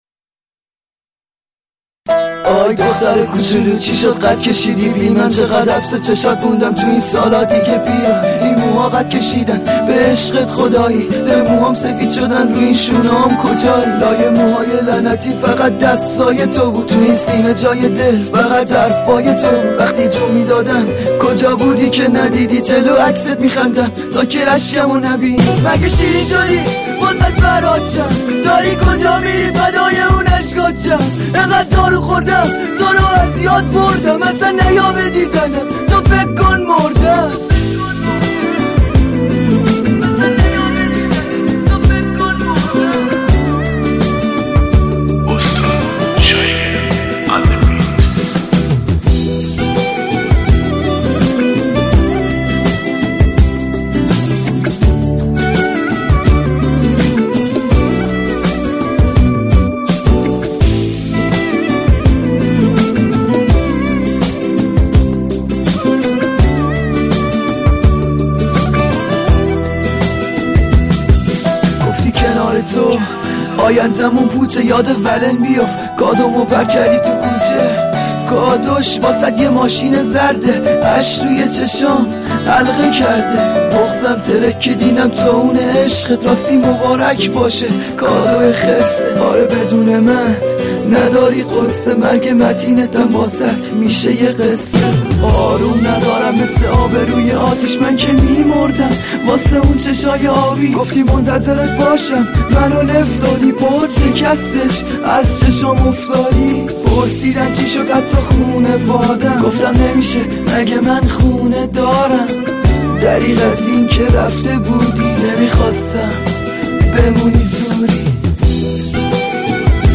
دیس لاو
آرام بخش